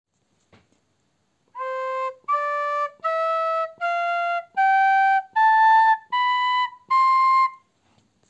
Look and listen to the Do M scale flute and then practise it with your own flute.